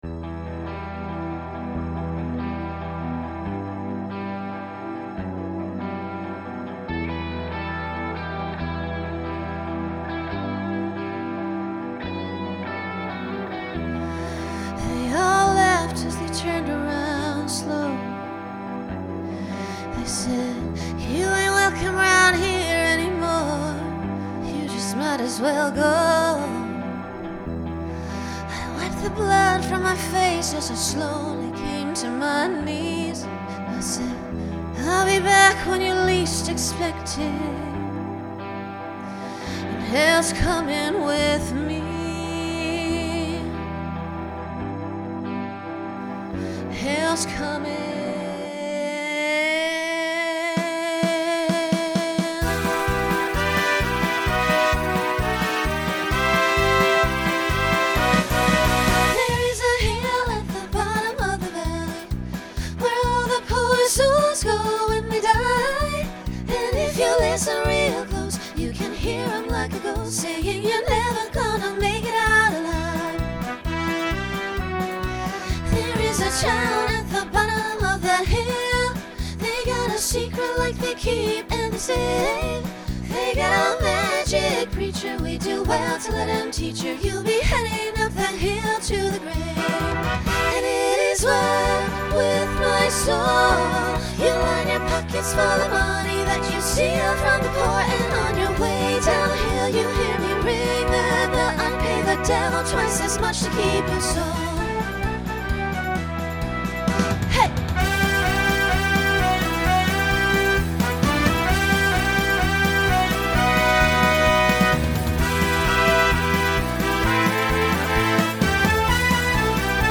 Genre Country Instrumental combo
Solo Feature Voicing SSA